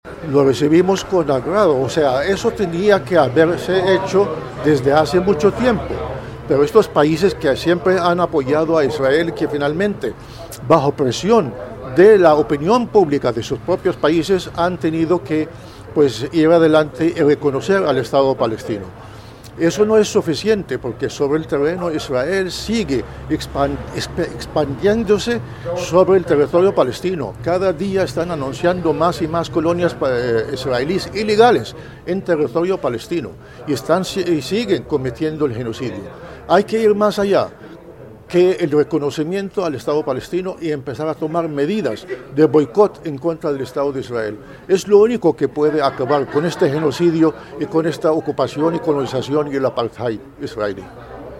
Embajador de Palestina en Colombia
En el marco de un foro liderado por el sindicado de educadores del Quindío sobre la paz estuvo como ponente el embajador de Palestina en Colombia, Raouf Al-Maliki quien se refirió a la crítica situación que vive su país por la guerra con Israel.